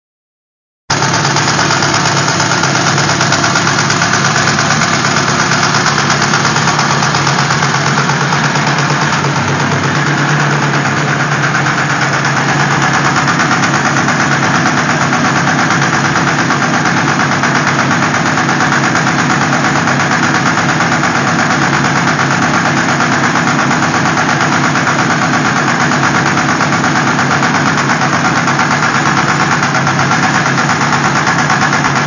Geräusch: klappern / tackern - Motor
Wenn ich dann kurz Gas gebe und wieder los lasse, dann hört man das Tackern etwas deutlicher.
Ich habe das mal mit dem Smartphone aufgenommen: